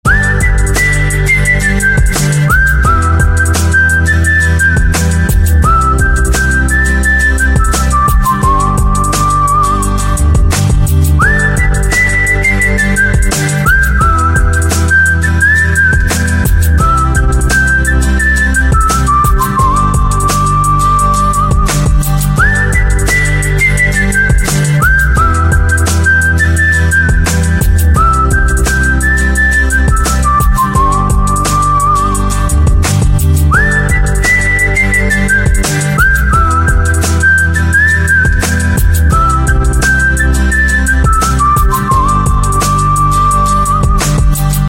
• Качество: 152, Stereo
романтичные